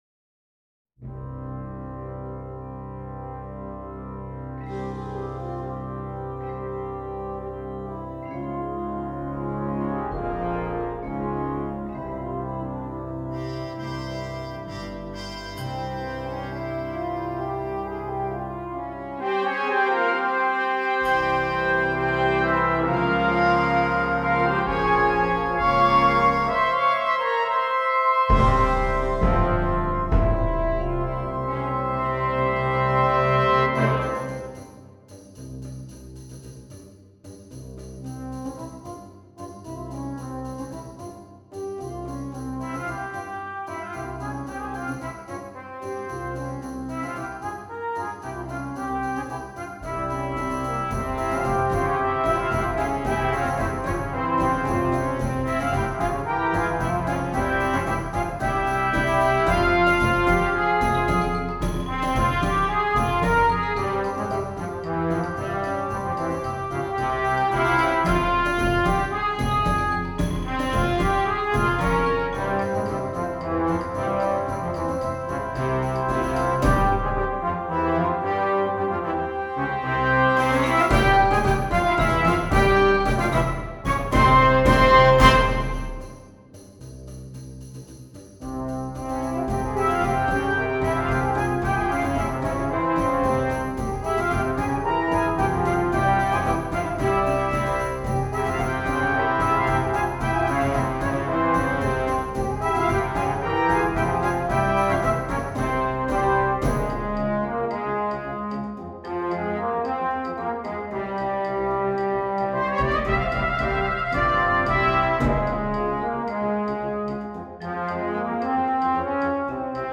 Voicing: Brass Choir